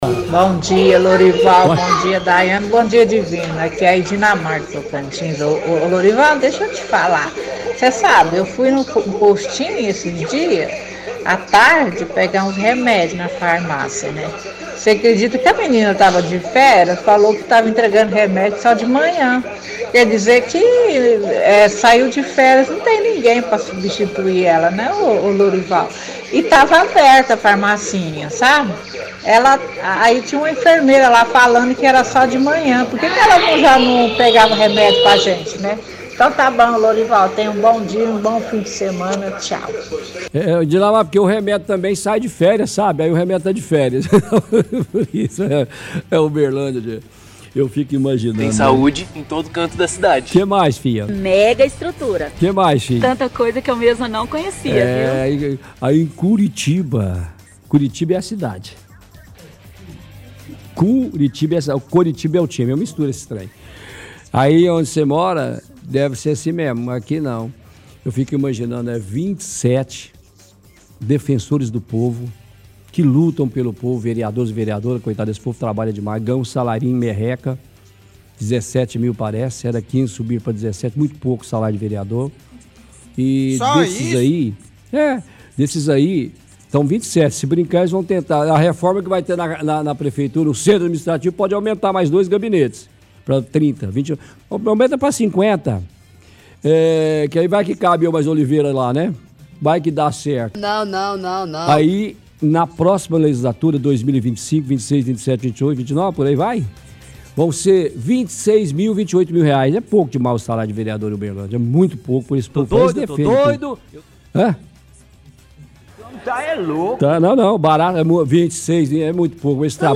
– Ouvinte do bairro Tocantins reclama do posto de saúde. Ela diz que foi ao postinho pegar medicamentos, mas recepcionista estava de folga e não tinha ninguém para atende-la.